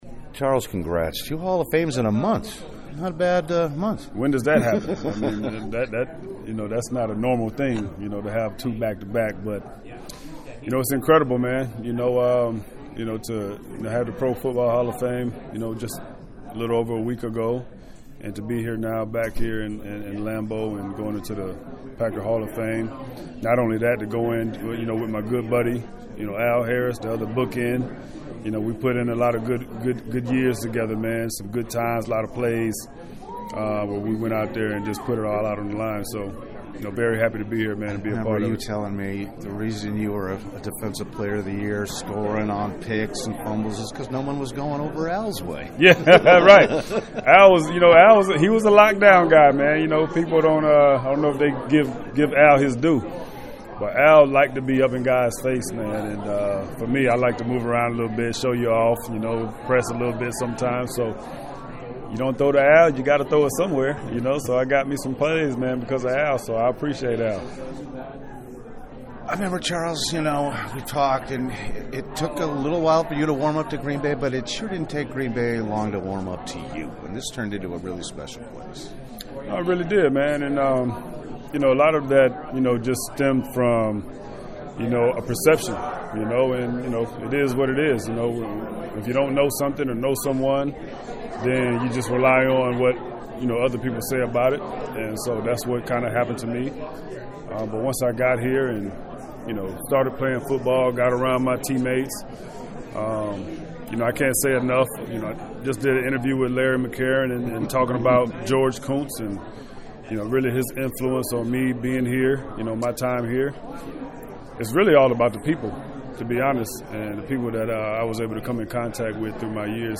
The quintessential pro, Woodson was always a fascinating interview in the locker room and it was more of the same when I caught up with “Sir Charles” on his way to the Packers Hall of Fame.